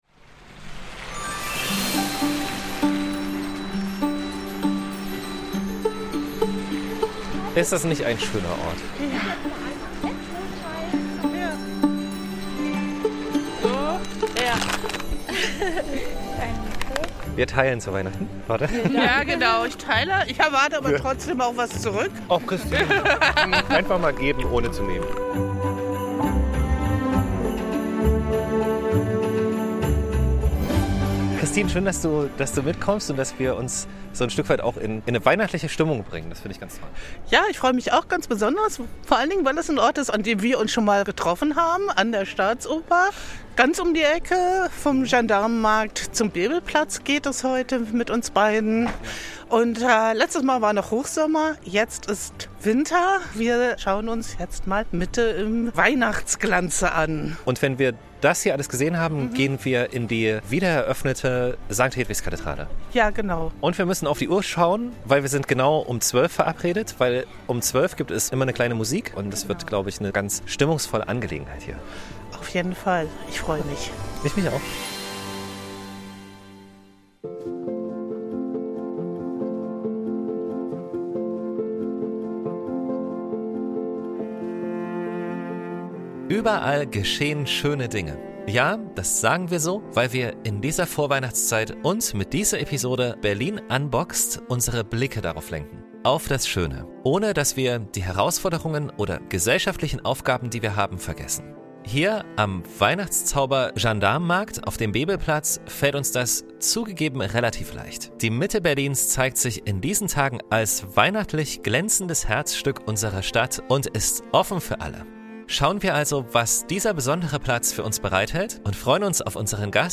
Heute nehmen wir euch mit auf eine kleine weihnachtliche Entdeckungstour zu den großen Sehenswürdigkeiten und zeigen euch wo ihr innehalten, schauen und zuhören könnt, wo Berlins Herz ruhiger schlägt und die Welt in weihnachtlich-warmen Glanz getaucht ist.